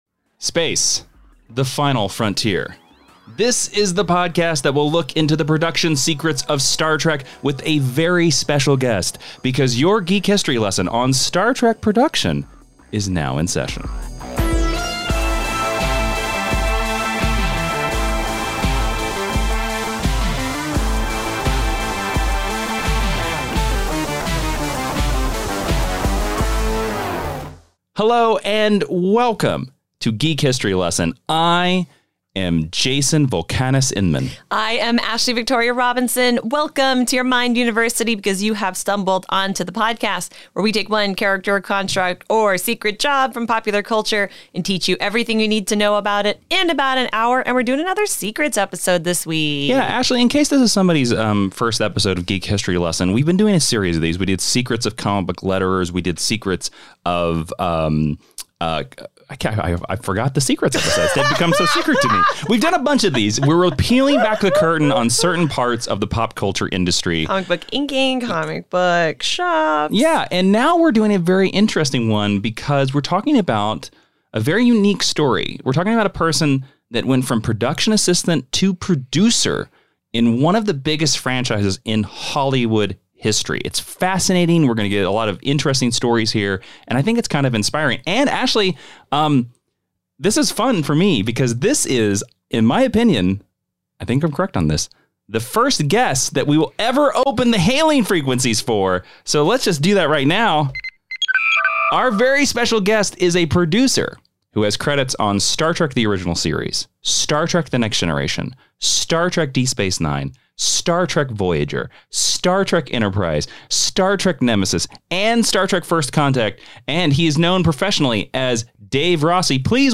This was a really great interview.